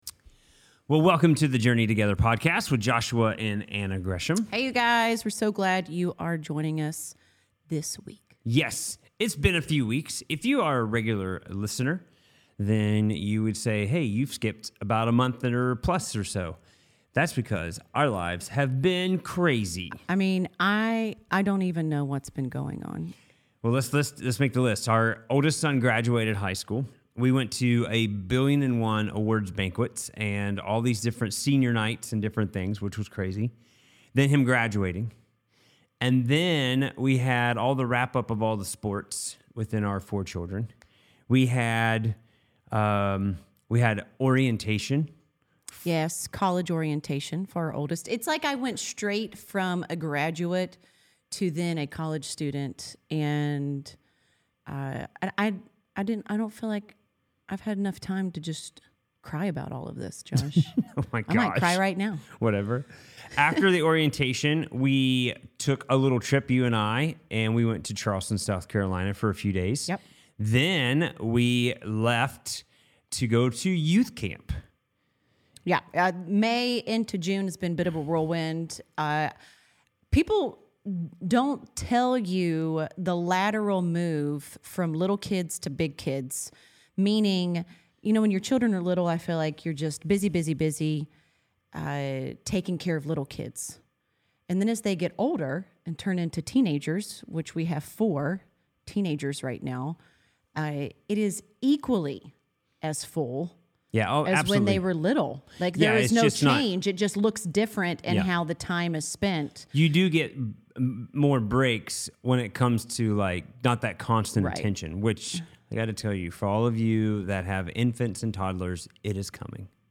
Whether you’re newlyweds or have been together for decades, tune in for an honest and inspiring conversation about what it takes to build and sustain a happy marriage.